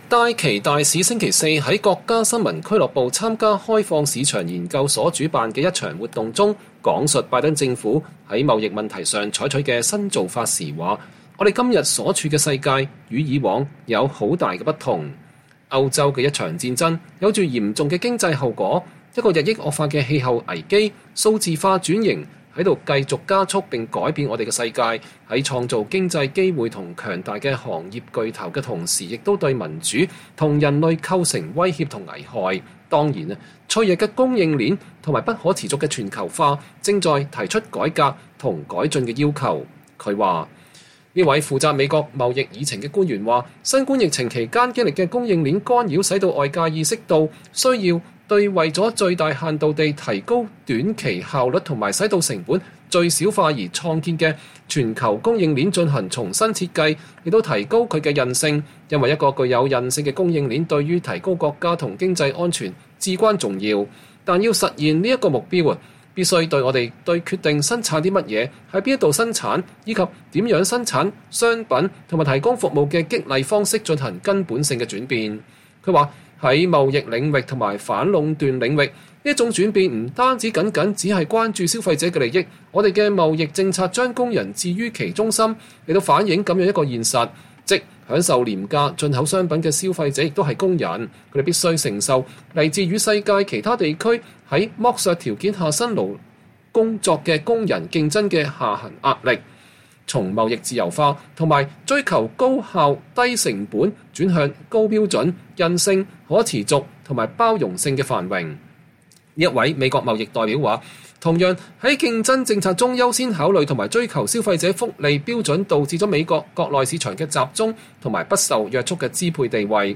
美國貿易代表戴琪在開放市場研究所主辦的有關世界貿易體系的活動上闡述拜登政府的貿易政策（2023年6月15日）。
戴琪大使星期四在國家新聞俱樂部參加開放市場研究所主辦的一場活動中闡述拜登政府在貿易問題上採取的新做法時說，我們今天所處的世界與以往有很大的不同。